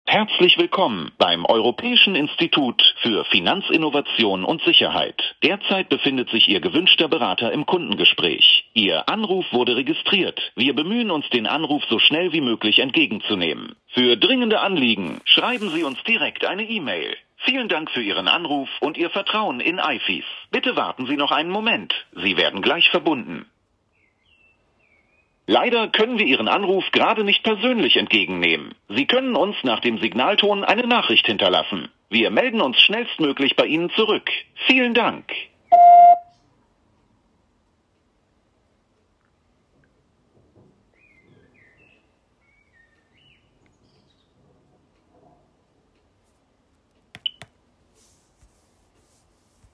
Wer die genannte Rufnummer anruft erhält nur einen Ansagetext vom Band.
(Audiomitschnitt 21.05.2025, 7:14 Uhr)